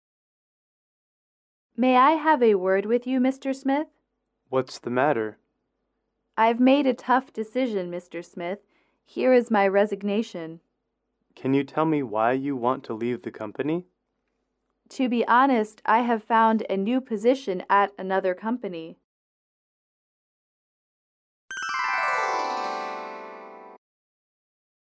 英语口语情景短对话34-3：递交辞呈(MP3)